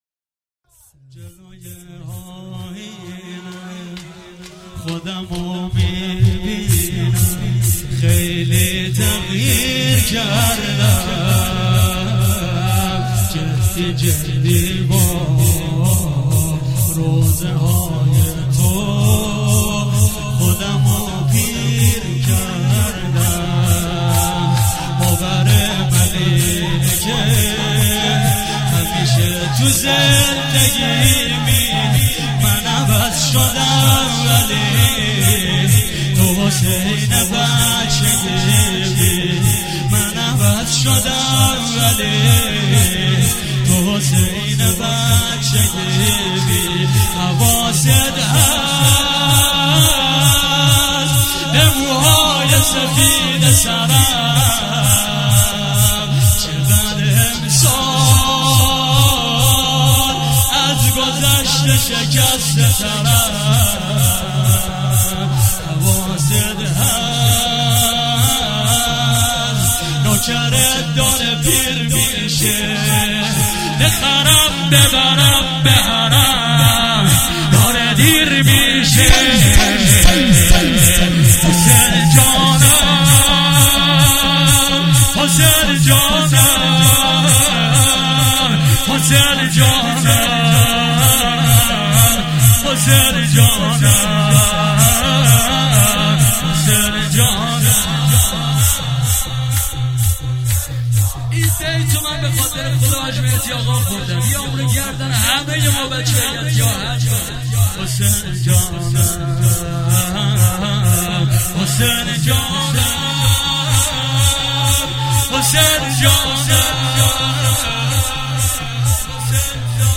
عنوان استقبال از ماه مبارک رمضان ۱۳۹۸
شور